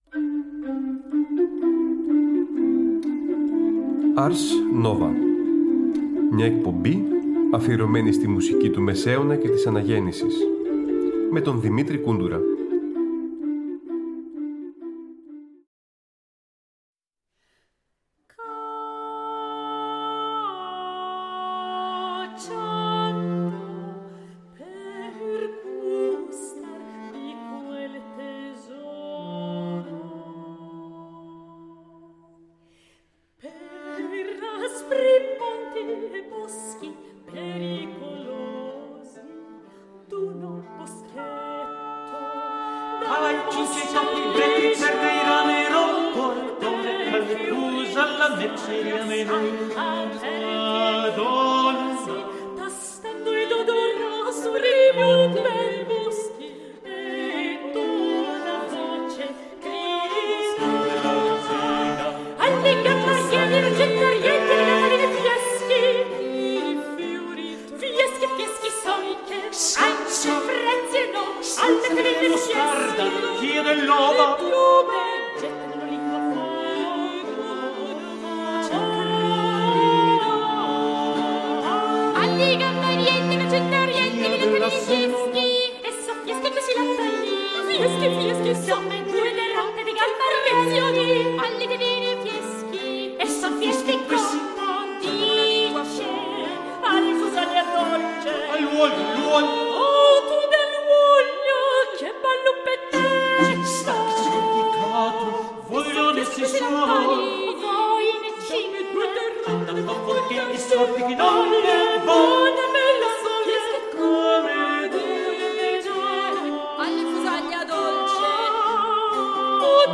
Ωριαία μουσική εκπομπή του Τρίτου Προγράμματος που μεταδίδεται κάθε Τρίτη στις 19:00.